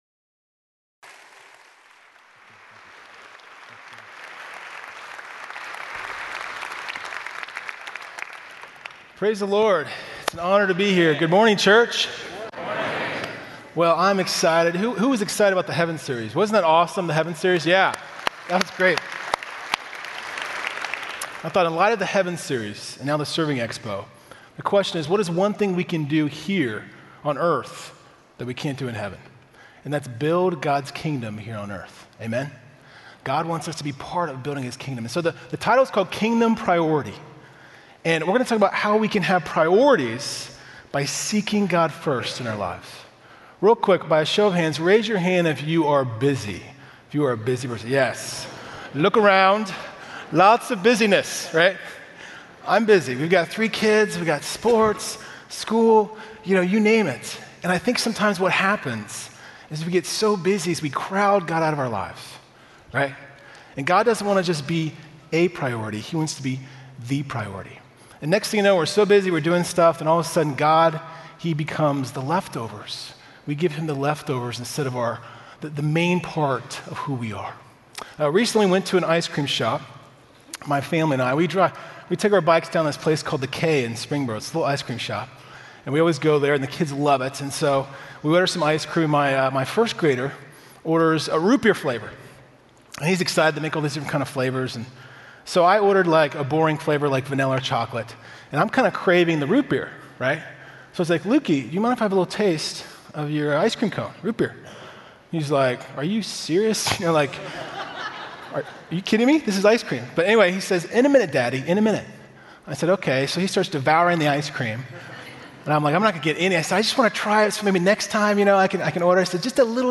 Check out Kingdom Priority, a sermon series at Fairhaven Church.